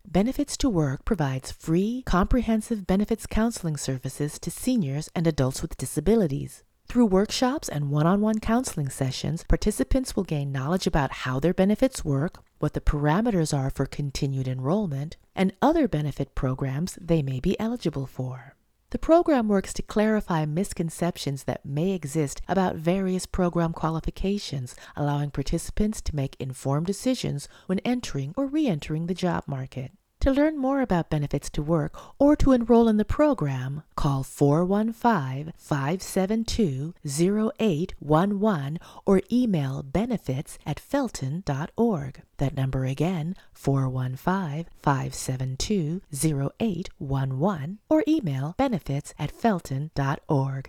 50-Second BFITS 2 Work PSA: